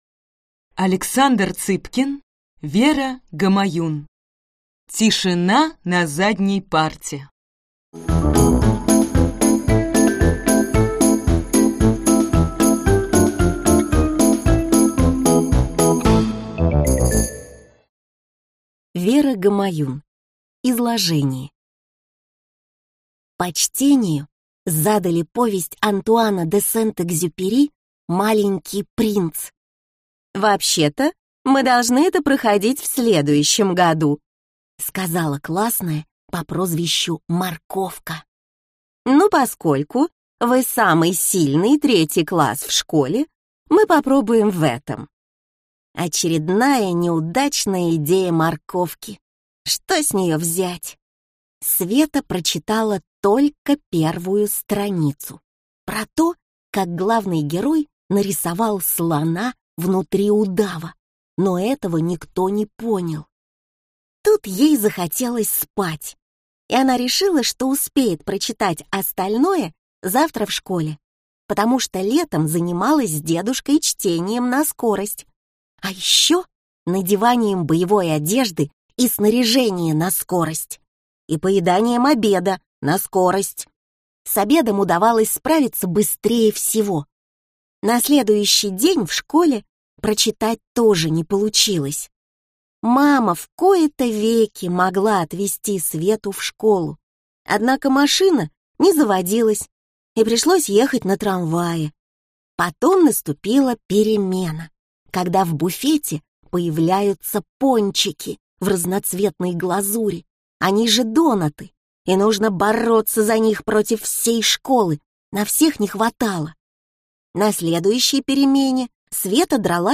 Аудиокнига Тишина на задней парте!
Прослушать и бесплатно скачать фрагмент аудиокниги